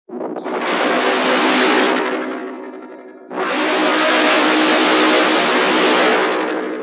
描述：我制作的低音听起来就像一个潜伏在远处的巨型怪物。
Tag: 生物 低音 环境 怪物